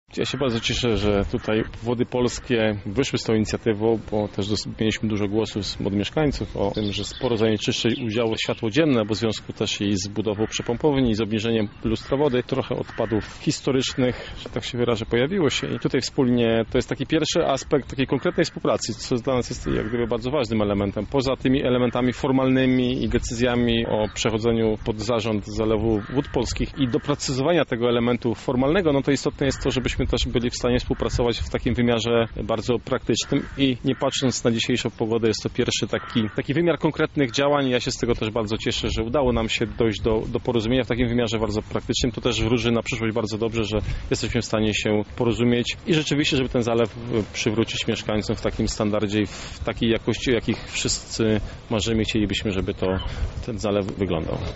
O Wodach Polskich i jej udziale w sprzątaniu mówi Artur Szymczyk, zastępca prezydenta miasta oraz dyrektor do spraw inwestycji i rozwoju: